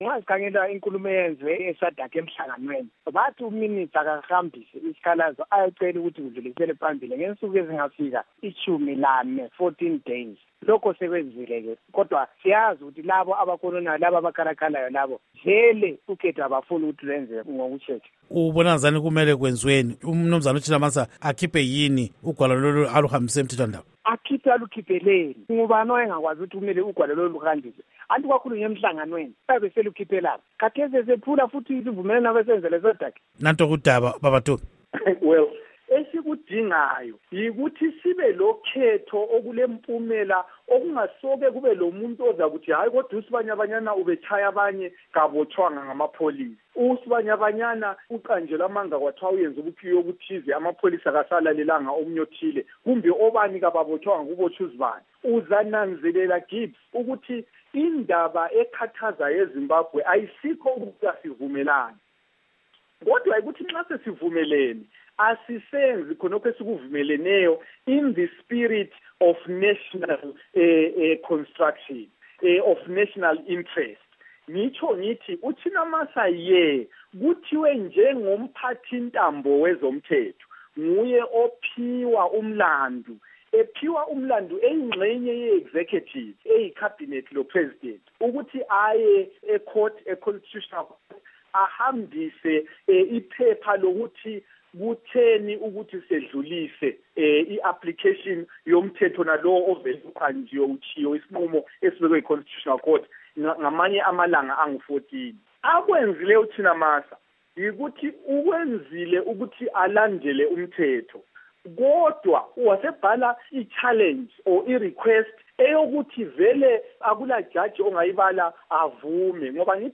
Inxoxo Esiyenze LoMnu.